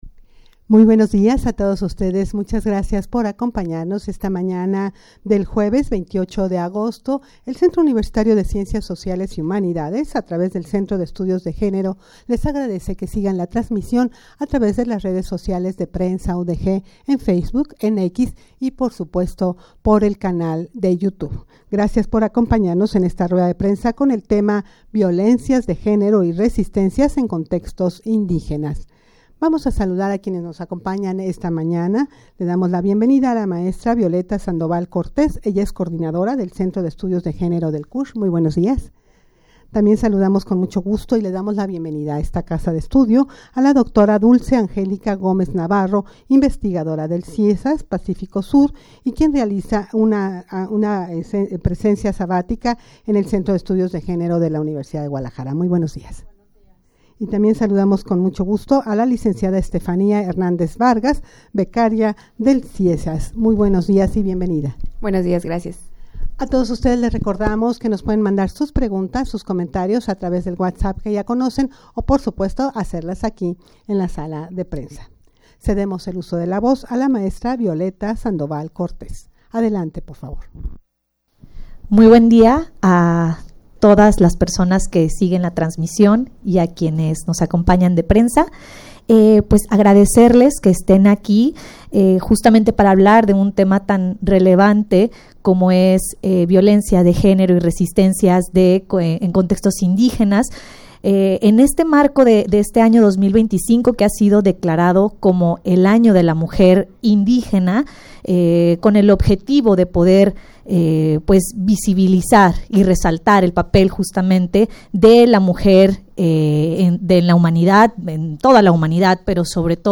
Audio de la Rueda de Prensa
rueda-de-prensa-con-el-tema-violencias-de-genero-y-resistencias-en-contextos-indigenas.mp3